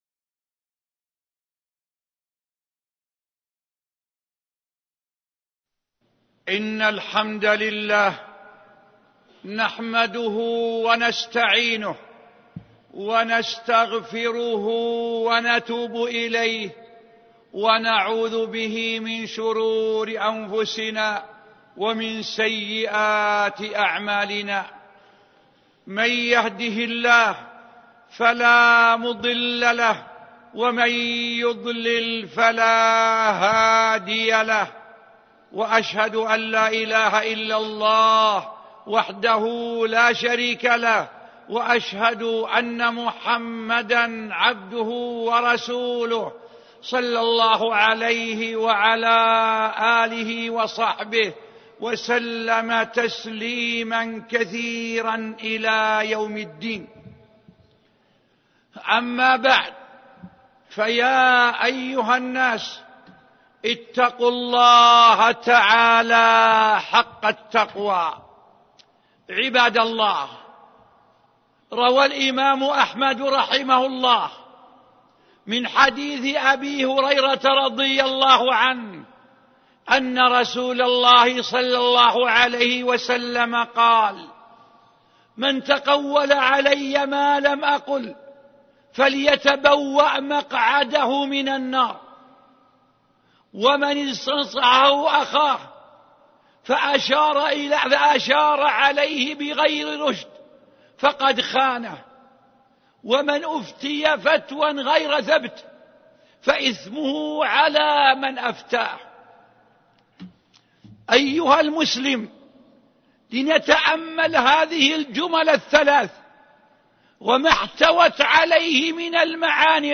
شبكة المعرفة الإسلامية | الدروس | الغش |عبد العزيز آل الشيخ